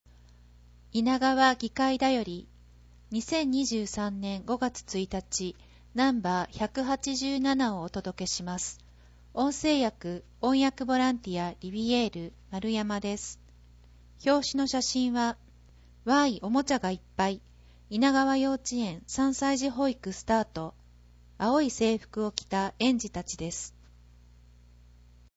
制作は猪名川町社会福祉協議会 音訳ボランティア リヴィエールの方々の協力によるものです。